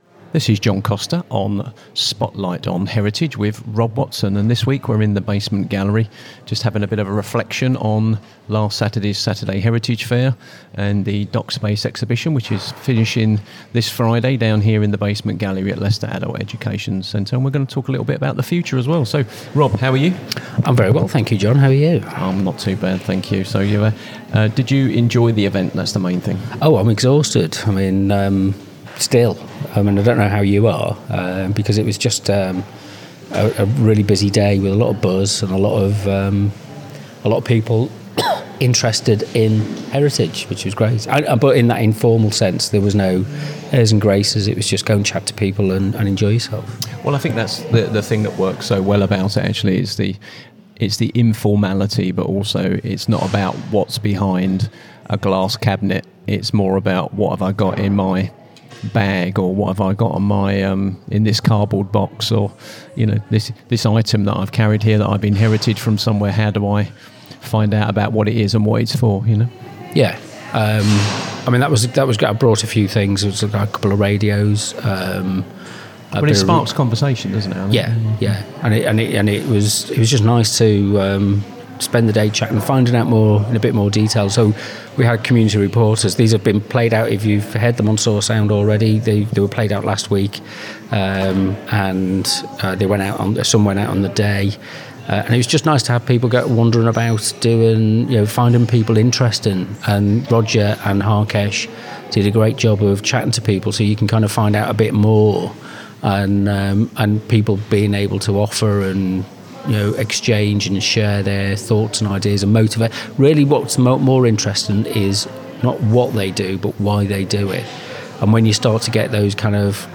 Recorded in the basement gallery of Leicester Adult Education Centre, the discussion explores how informal heritage events foster connection, trust, and collaboration across diverse groups. The conversation also considers the future of community reporting, the need for authentic and independent media, and the importance of sustainable local funding models that strengthen civic and cultu